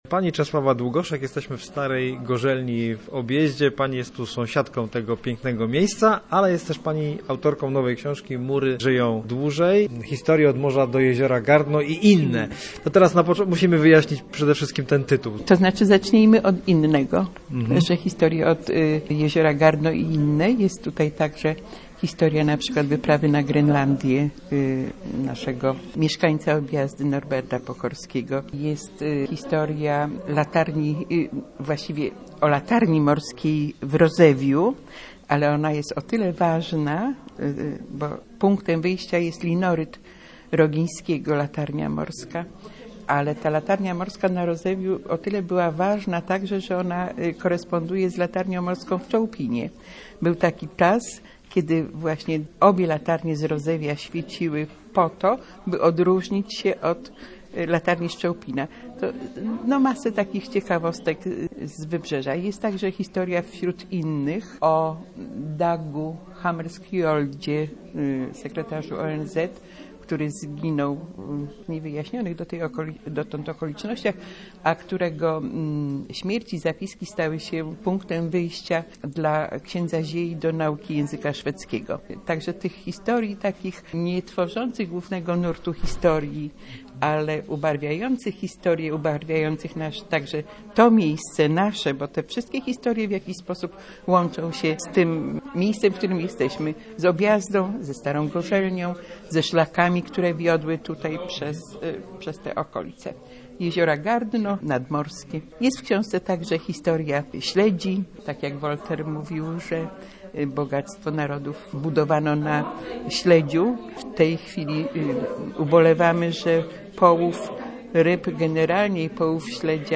książka region rozmowa